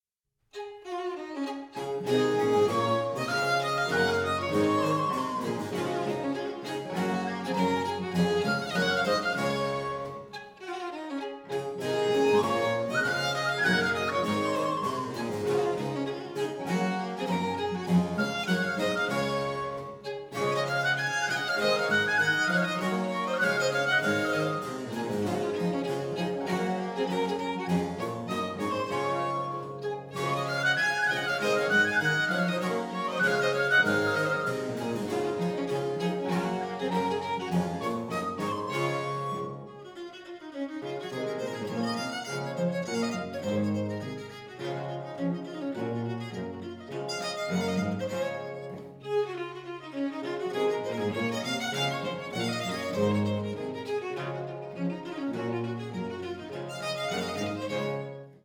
recorders and whistle
baroque violin
baroque cello and viola da gamba
harpsichord
theorbo and baroque guitar
percussion